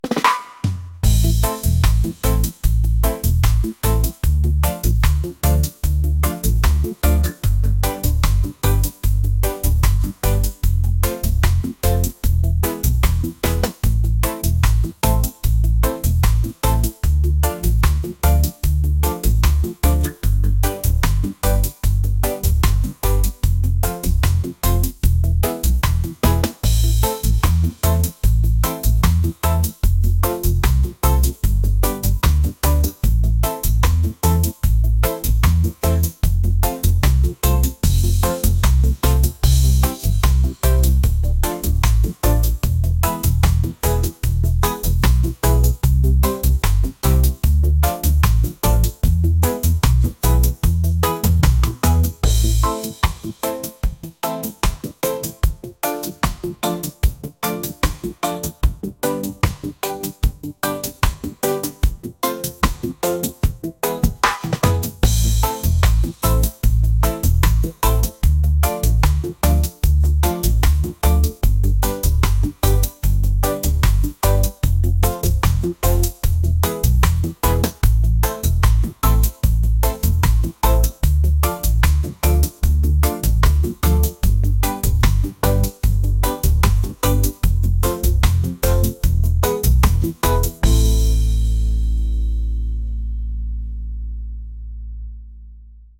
reggae | lounge | acoustic